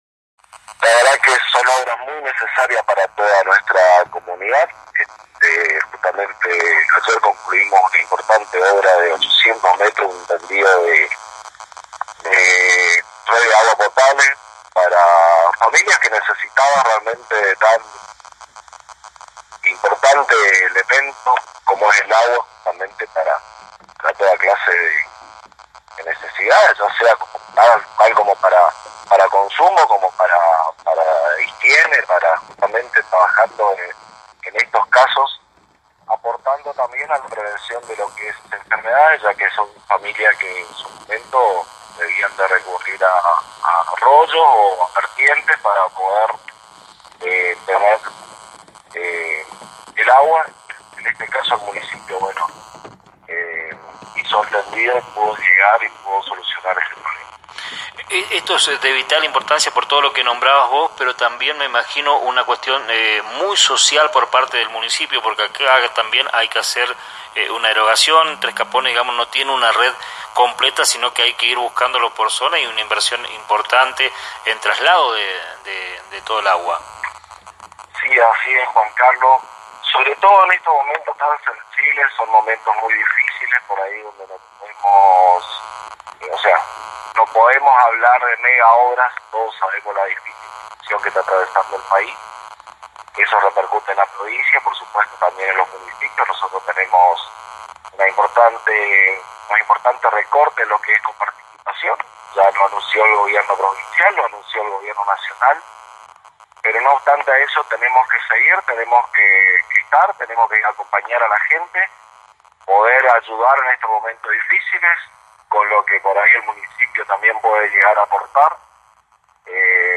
En diálogo con el Intendente de Tres Capones Ramón Gerega.